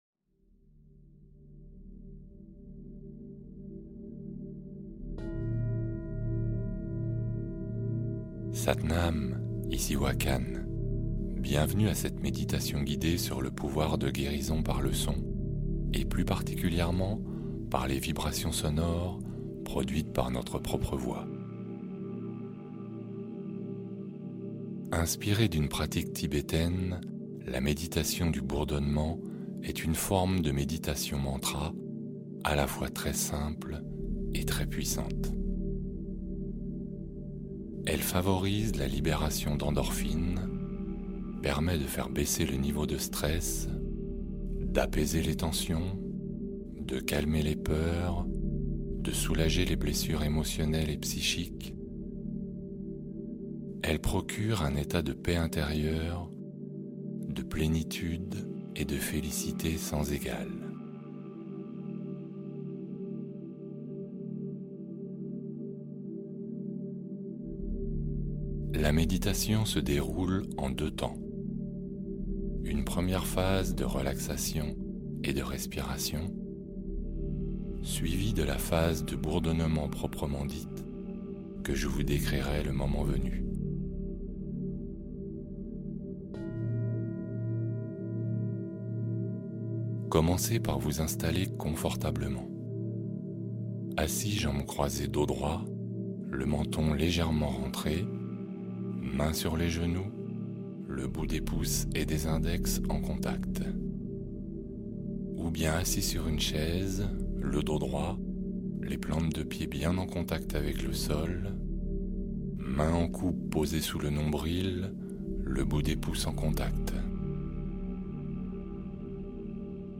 Soin par le Son : La vibration du OM pour régénérer son être